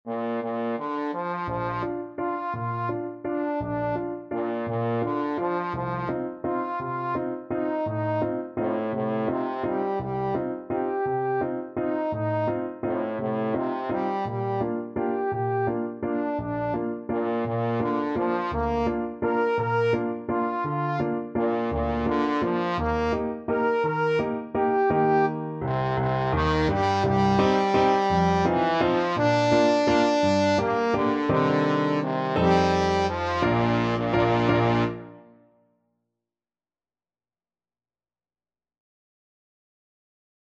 Trombone
3/4 (View more 3/4 Music)
=169 Steady one in a bar
Bb major (Sounding Pitch) (View more Bb major Music for Trombone )
Classical (View more Classical Trombone Music)